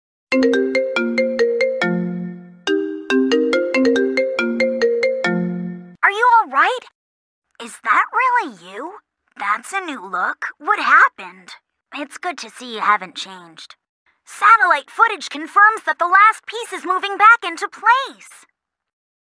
📌 Disclaimer: This is a fun fake call and not affiliated with any official character or franchise.